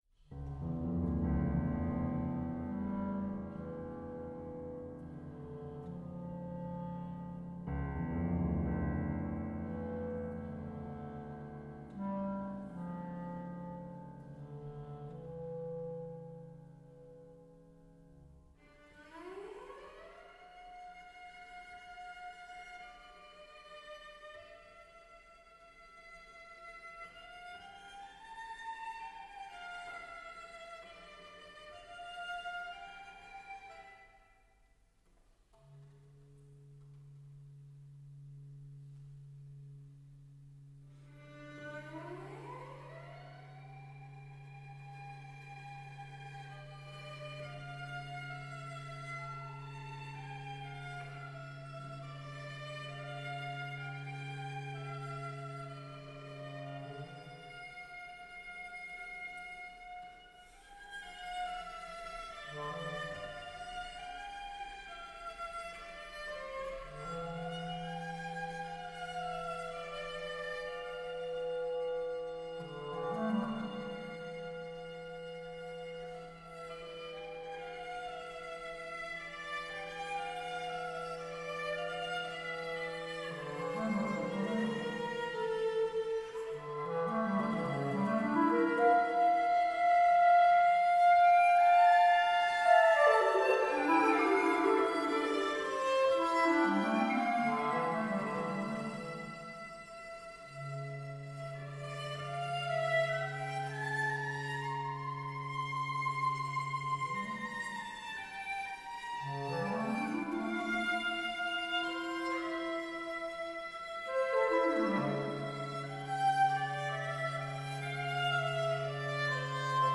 Genre: Instrumental chamber music
Instrumentation: clarinet, cello, piano